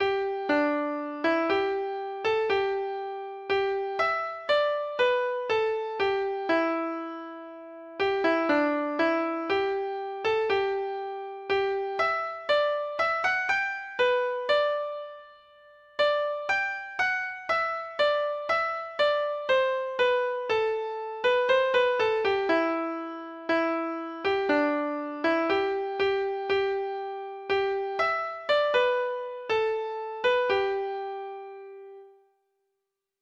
Folk Songs from 'Digital Tradition' Letter T The Lowlands of Holland
Free Sheet music for Treble Clef Instrument